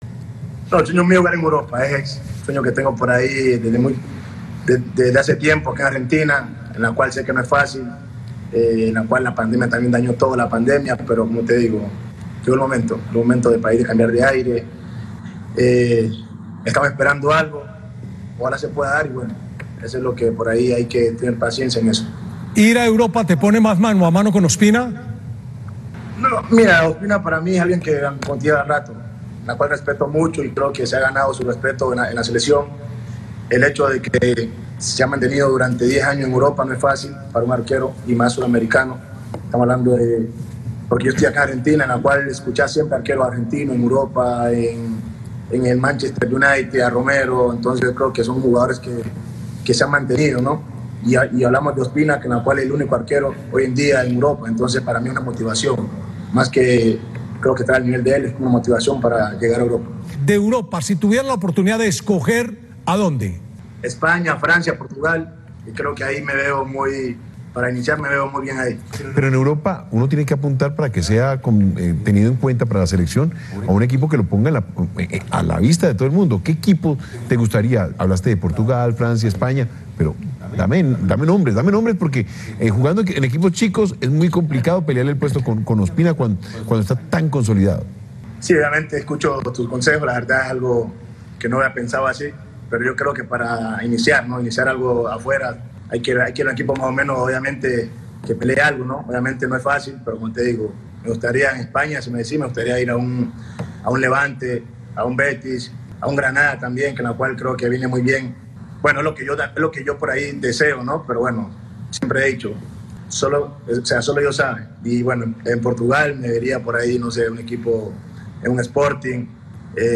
portero colombiano, en diálogo con ESPN